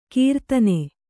♪ kīrtane